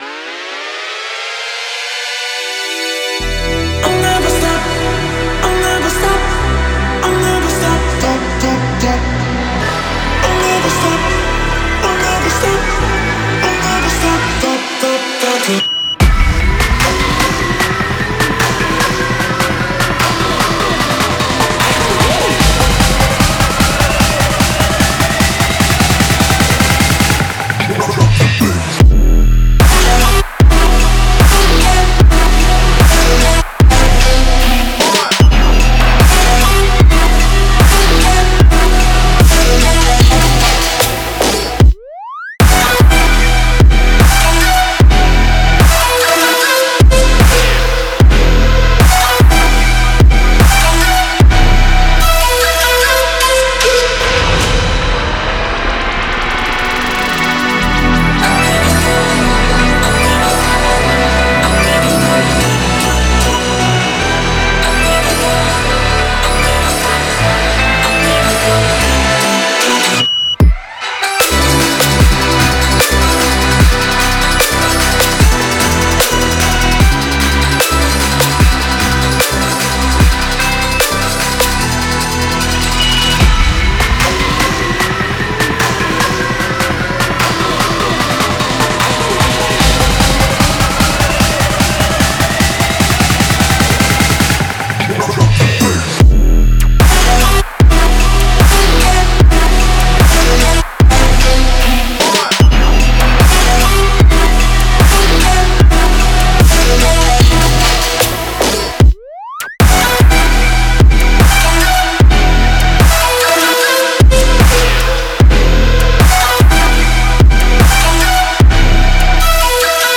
Trap, Epic, Dreamy, Happy, Euphoric, Energetic